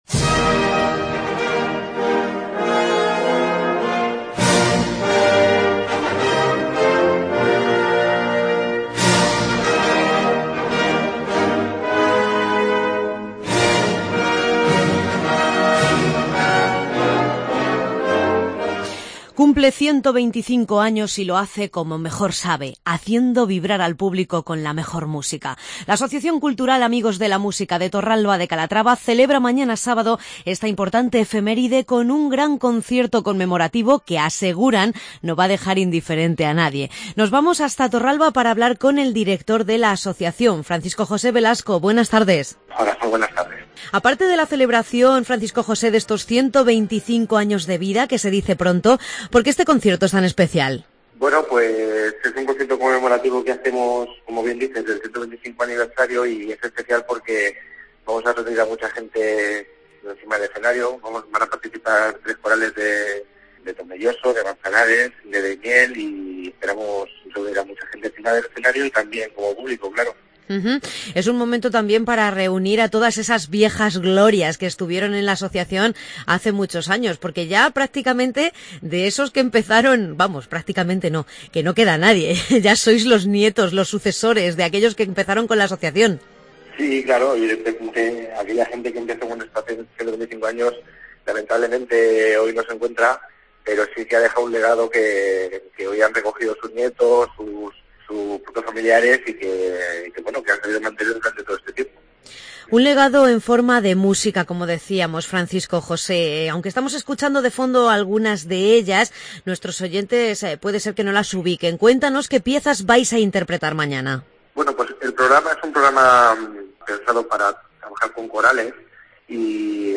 Concierto 125 aniversario Banda de Música de Torralba